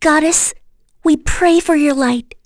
Frey-vox-get.wav